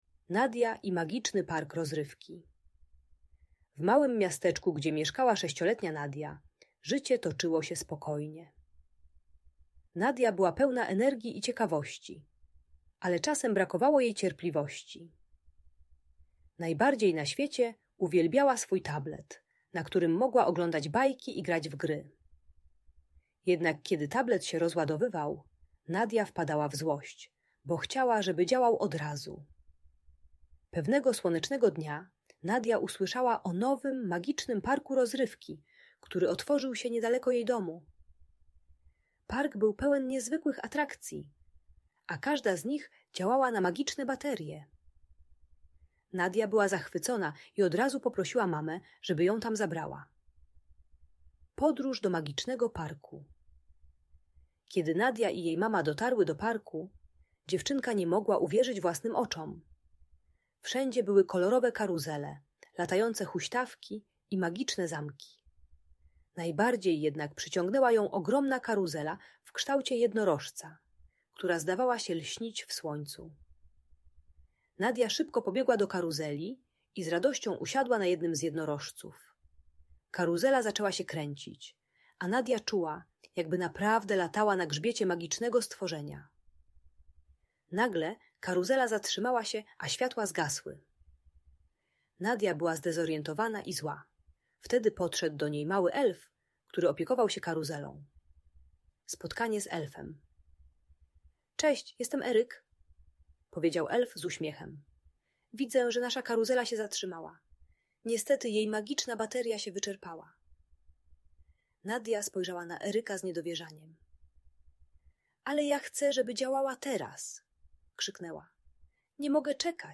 Uczy cierpliwości i radzenia sobie z frustracją gdy elektronika nie działa. Audiobajka o zdrowym podejściu do ekranów i czekania.